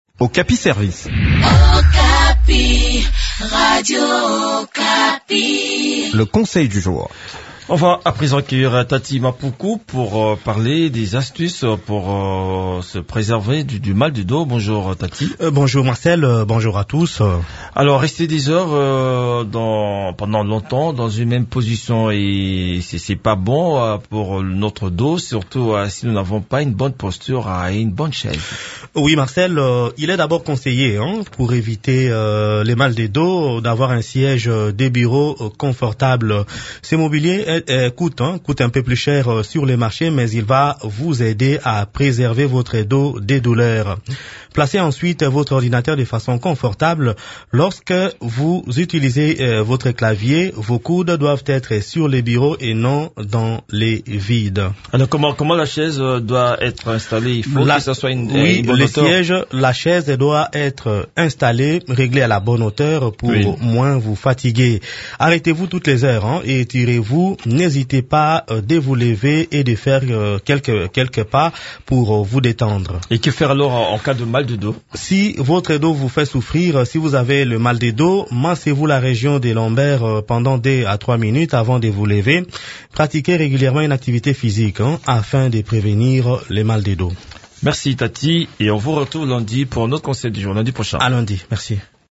Découvrez des astuces qui peuvent vous aider à éviter des douleurs de dos dans cette chronique